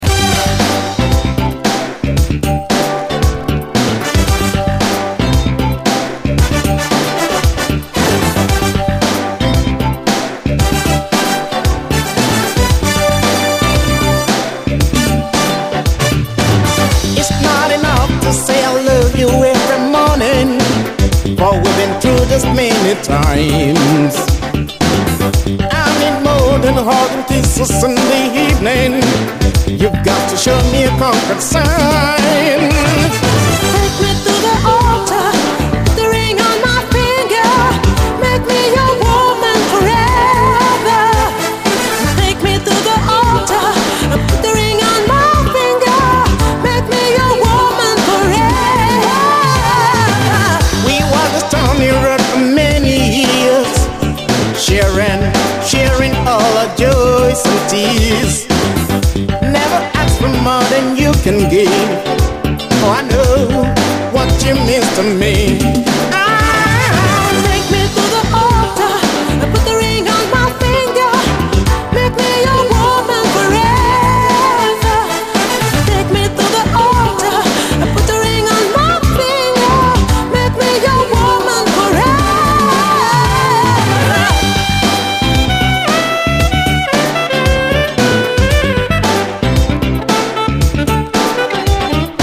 内容最高のUK産モダン・ソウル人気盤！
爽やかなトロピカル・ソウルへと展開するキラー・チューンです！
ラテン・ダンサー
アーバン・ブギー・ソウル